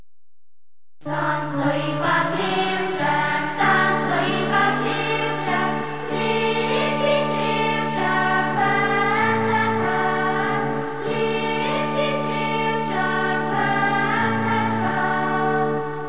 Children’s Christmas song TAM STOJI PA HLEVCEK (There is a small Stable) by Acko
The songs were sung by different choirs as: Ljubljanski oktet, Slovenski komorni zbor, Deseti brat oktet,